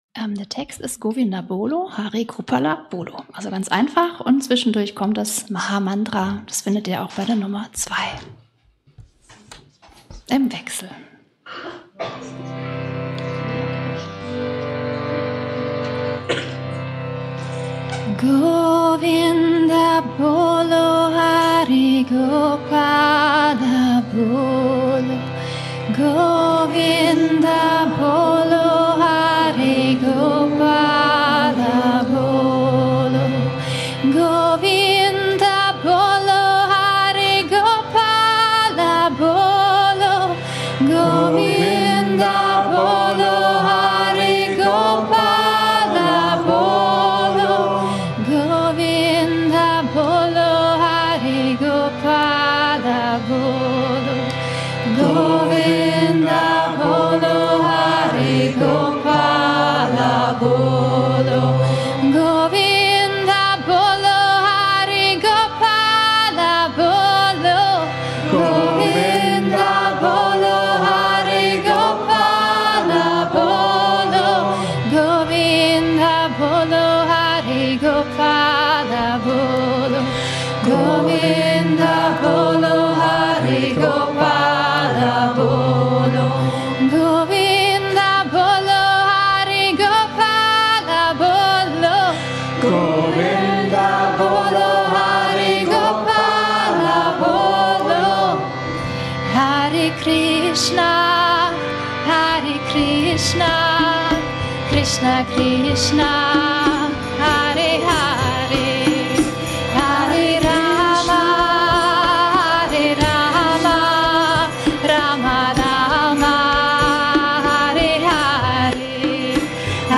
Aad Guray Nameh is a powerful mantra that gives protection, helps to become more clear, and aids to get guidance from one’s higher self. Chanting this mantra creates a protective field around the chanter and aids to and can attract abundance which may help the chanter to fullfill their souls path.
Here is the text to sing along: